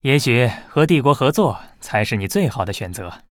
文件 文件历史 文件用途 全域文件用途 Bhan_fw_03.ogg （Ogg Vorbis声音文件，长度3.3秒，109 kbps，文件大小：44 KB） 源地址:地下城与勇士游戏语音 文件历史 点击某个日期/时间查看对应时刻的文件。